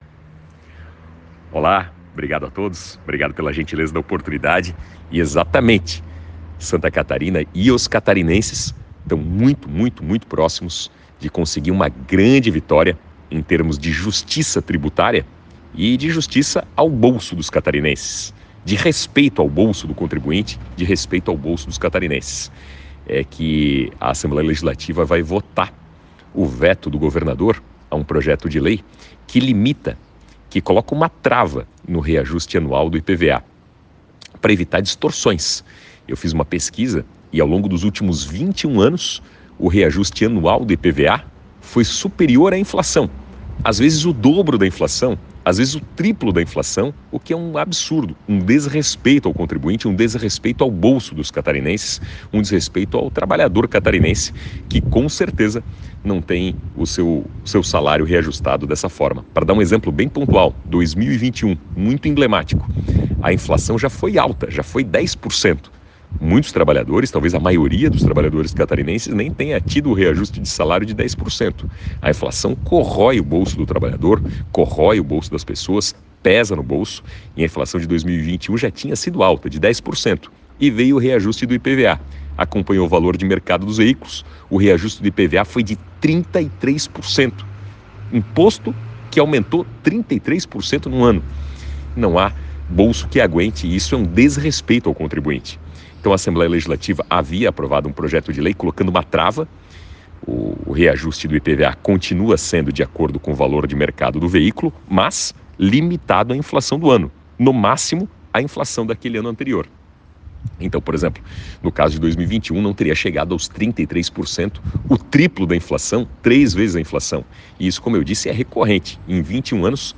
Áudio do deputado Napoleão Bernardes